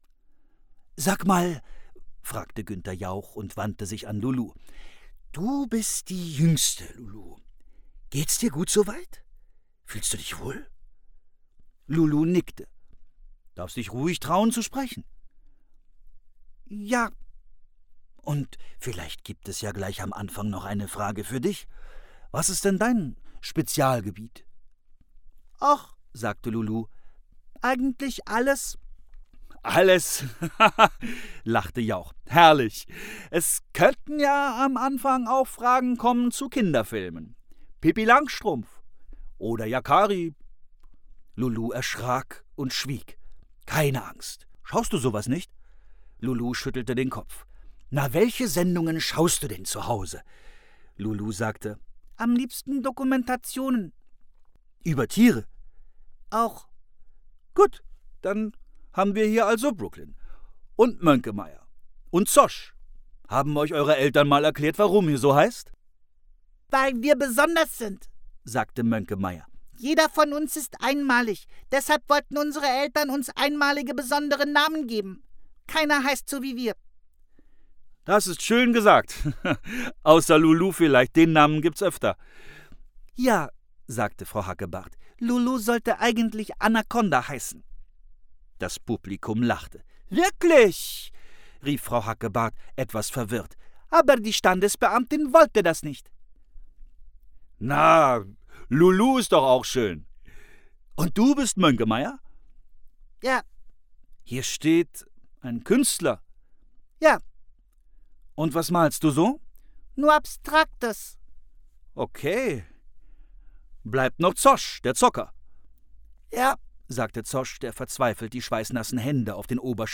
Markus Orths (Autor) Stefan Kaminski (Sprecher) Audio-CD 2023 | 1.